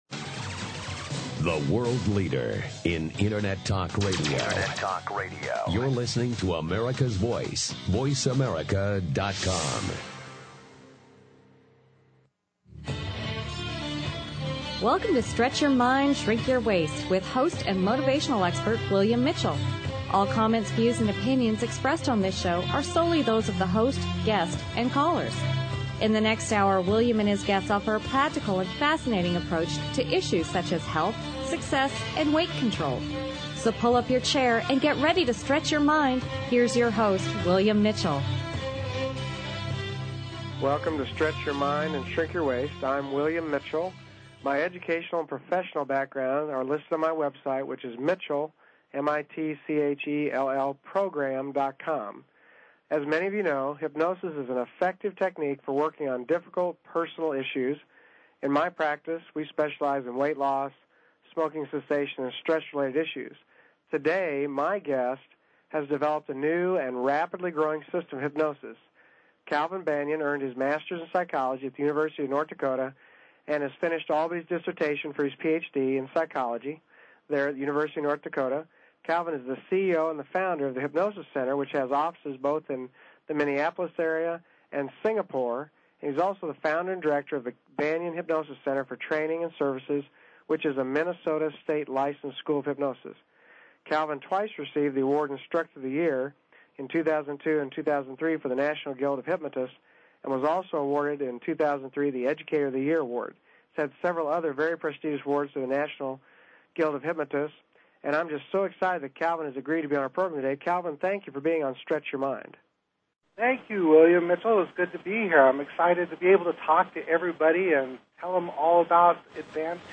In this hypnosis audio interview
Stretch Your Mind, Shrink Your Waist, Internet "radio talk show"